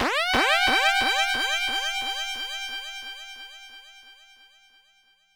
synth04.wav